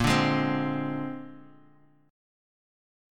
Bb9 Chord
Listen to Bb9 strummed